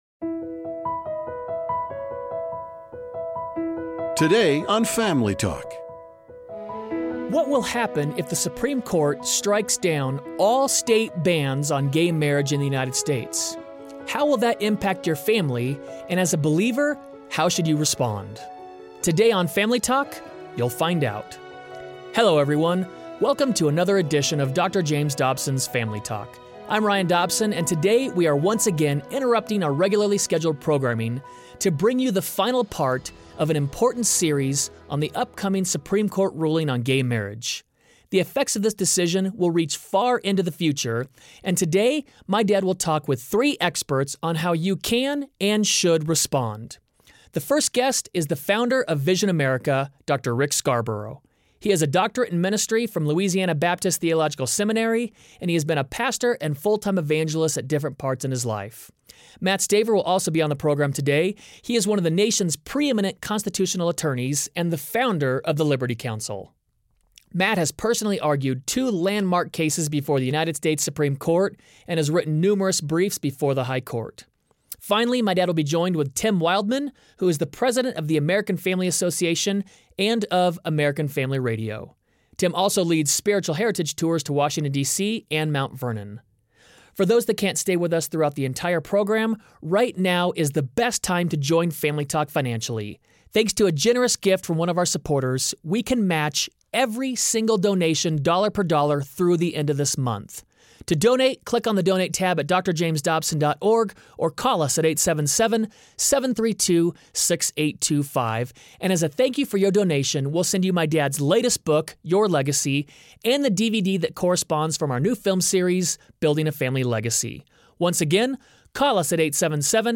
Most likely, we are months away from seeing gay marriage legalized in America. On the next edition of Family Talk, Dr. James Dobson interviews a panel of guests about what to expect and how to respond.